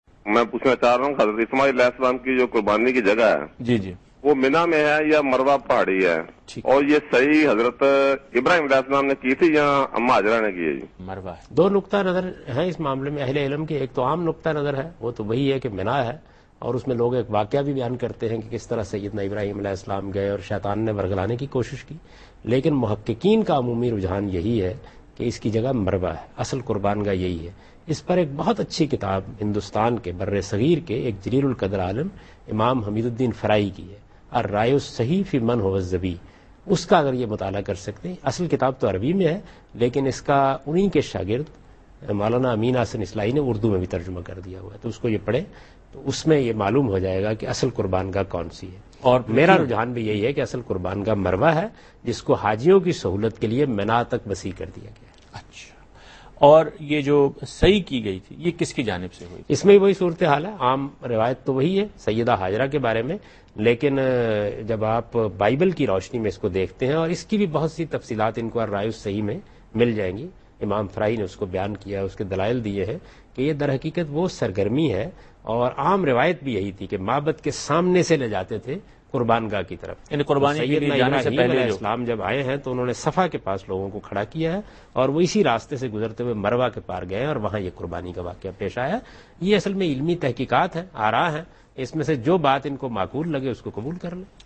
Category: TV Programs / Dunya News / Deen-o-Daanish /
Javed Ahmad Ghamidi answers a question about "Sacrifice of Ishmael (sws)" in program Deen o Daanish on Dunya news.
جاوید احمد غامدی دنیا نیوز کے پروگرام دین و دانش میں حضرت اسمٰعیل کی قربانی سے متعلق ایک سوال کا جواب دے رہے ہیں۔